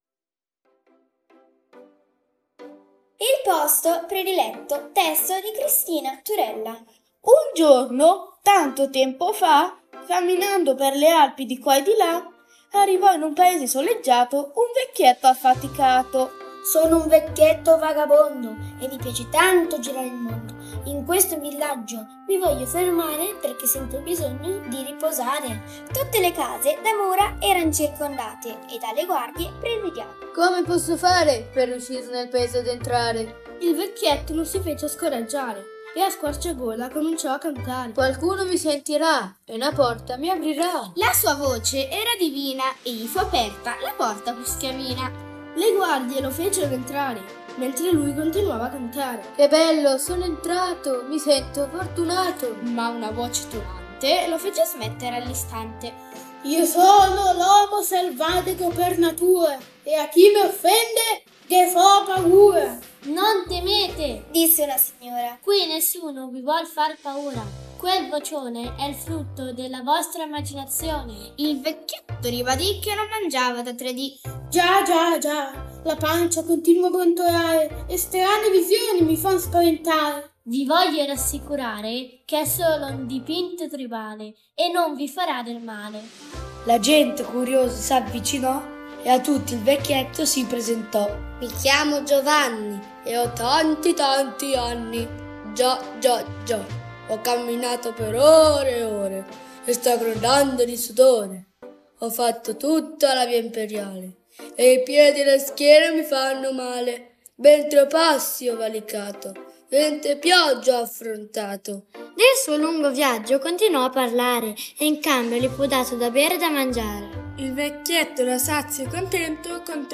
Un piccolo consiglio: Questa storia funziona ancora di più ad alta voce: puoi leggerla insieme ai bambini o ascoltarla qui sotto, come una filastrocca che prende ritmo strada facendo.